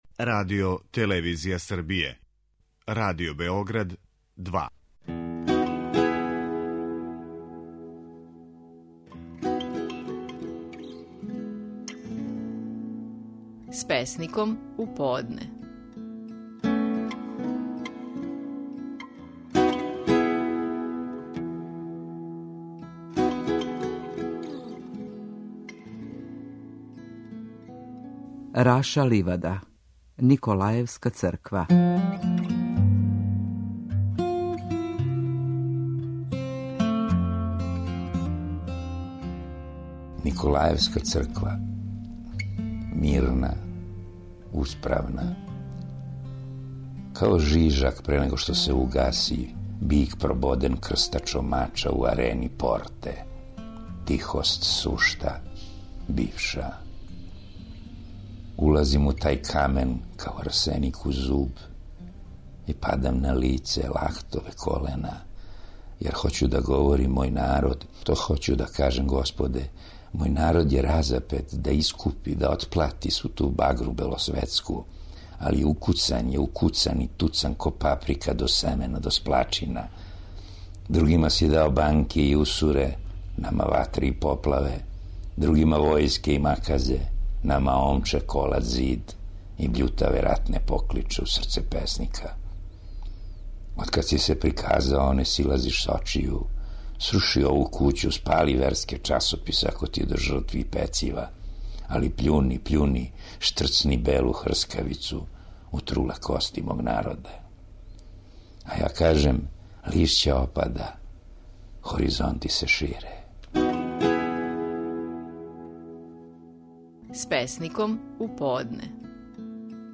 Наши најпознатији песници говоре своје стихове
У данашњој емисији, слушаћемо Рашу Ливаду и његову песму Николајевска црква.